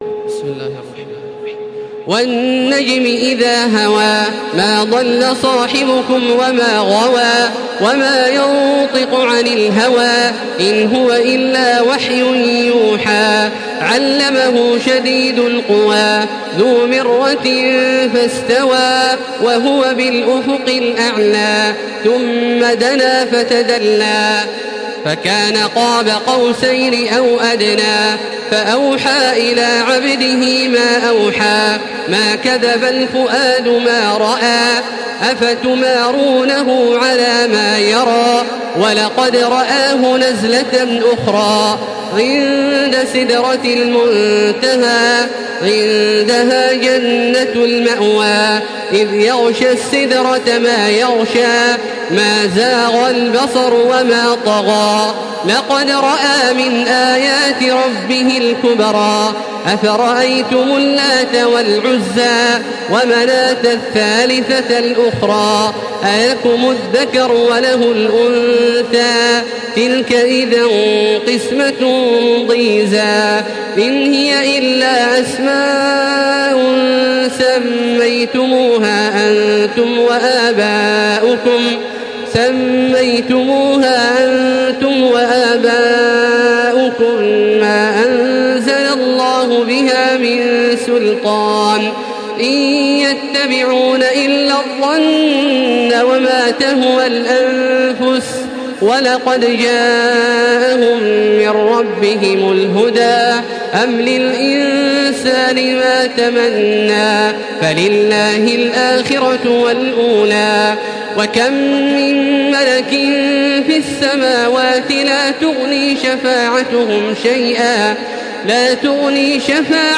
Surah An-Najm MP3 in the Voice of Makkah Taraweeh 1435 in Hafs Narration
Listen and download the full recitation in MP3 format via direct and fast links in multiple qualities to your mobile phone.